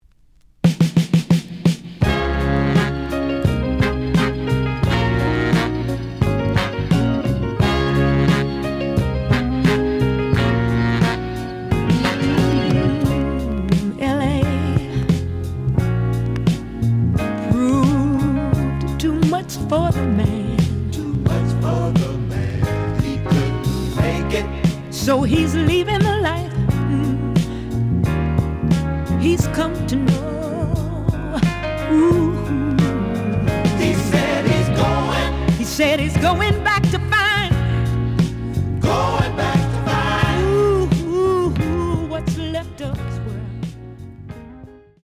The audio sample is recorded from the actual item.
●Genre: Soul, 70's Soul
Some damage on both side labels. Plays good.)